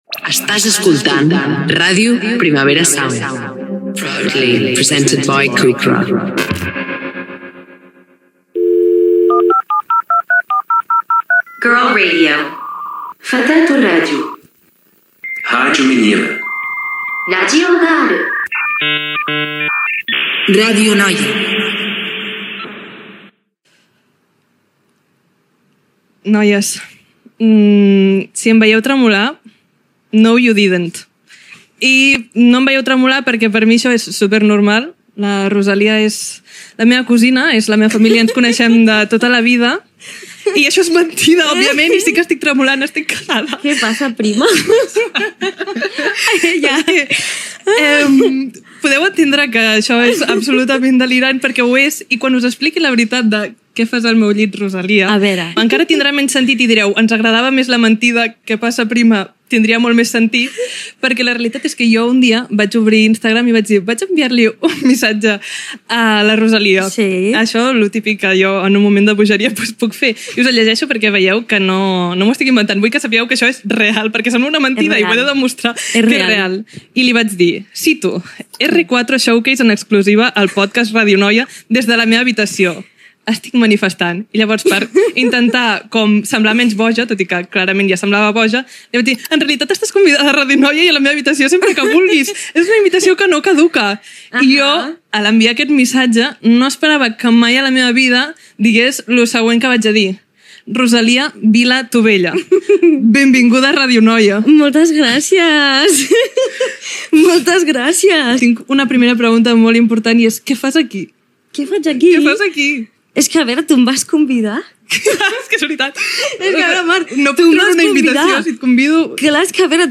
Entrevista a la cantant Rosalía (Rosalia Vila). Explica com ha passat l'estiu, com s'organitza en aquell moment que està preparant un nou disc